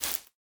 Minecraft Version Minecraft Version 1.21.5 Latest Release | Latest Snapshot 1.21.5 / assets / minecraft / sounds / block / cobweb / break6.ogg Compare With Compare With Latest Release | Latest Snapshot
break6.ogg